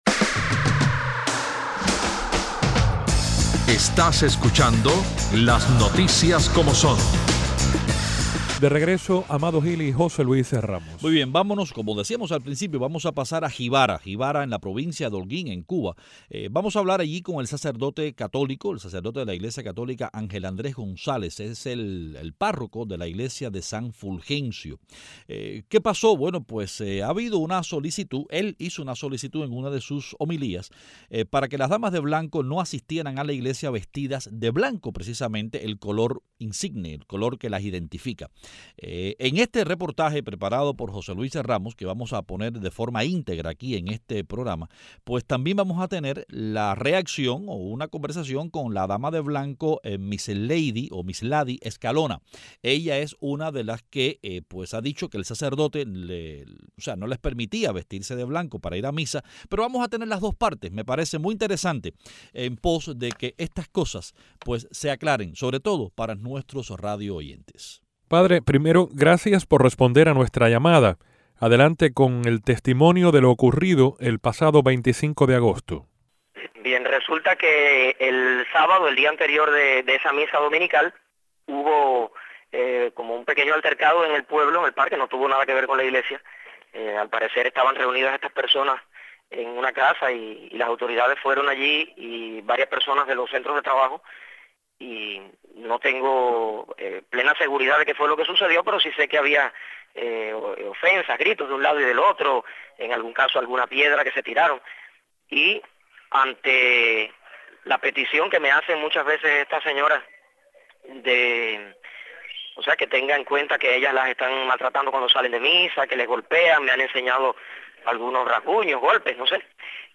Hablamos con el sacerdote católico